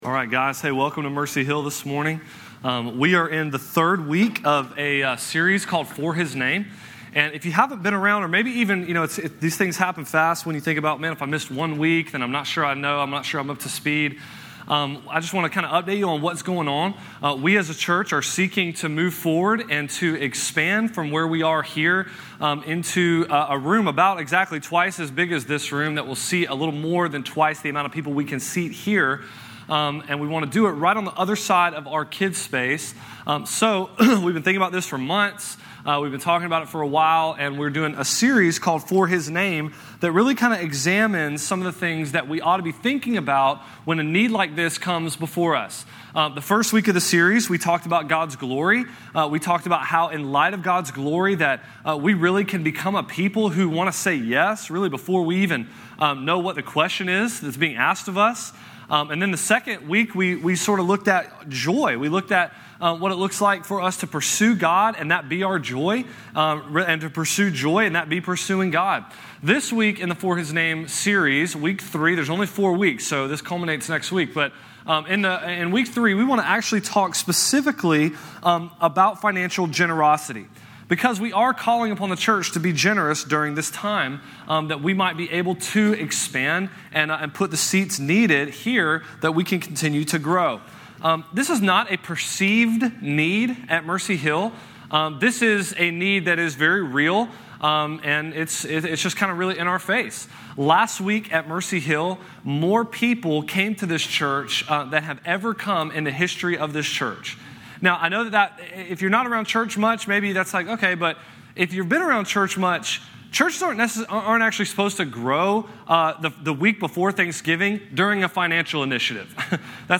The For His Name sermon series dives into what it means to do great things for the gospel.